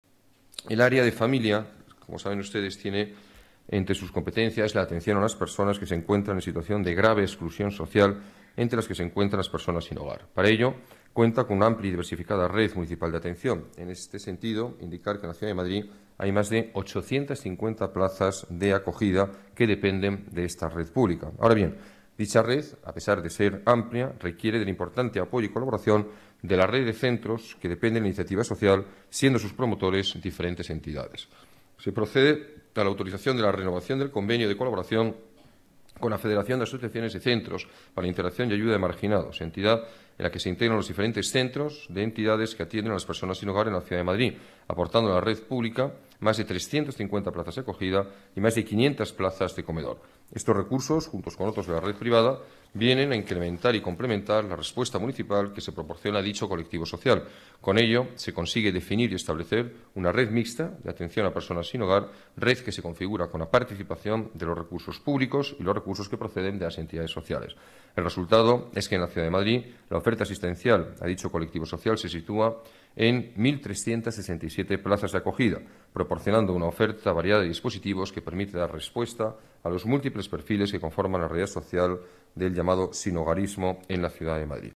Nueva ventana:Declaraciones del alcalde, Alberto Ruiz-Gallardón: Asistencia personas sin hogar